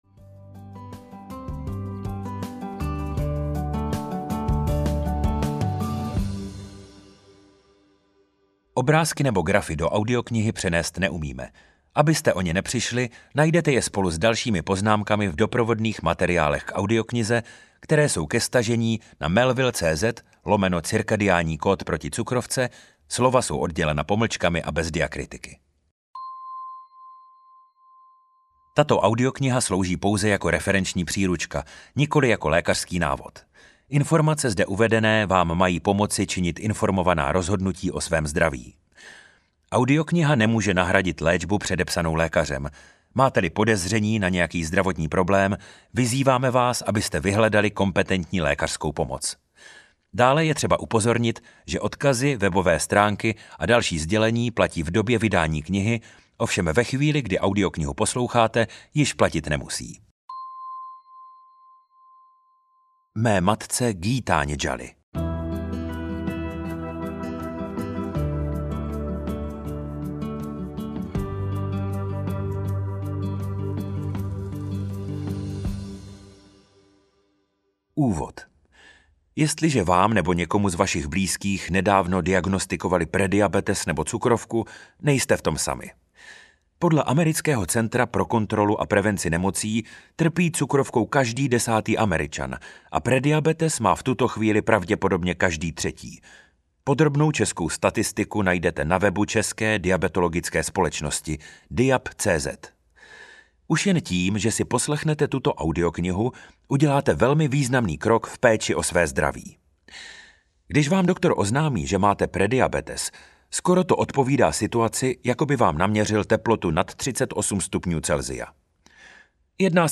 Cirkadiánní kód proti cukrovce audiokniha
Ukázka z knihy